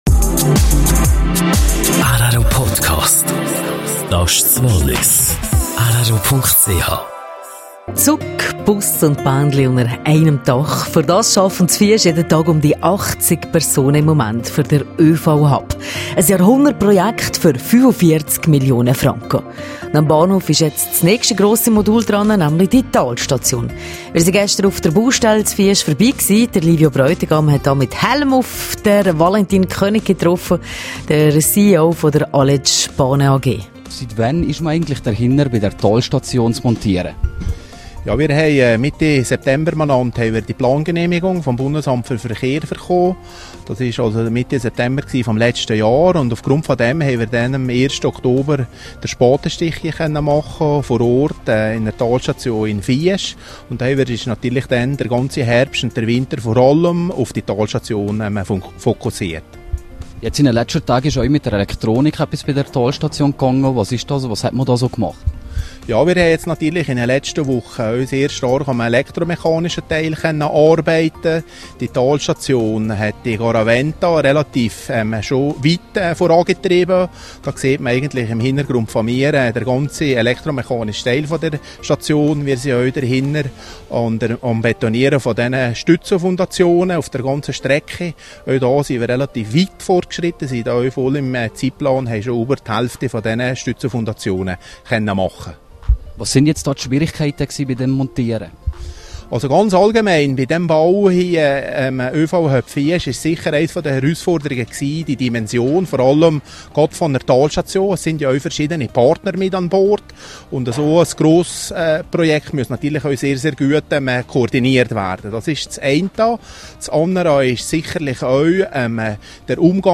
Baustellenbesuch beim öV-Hub in Fiesch.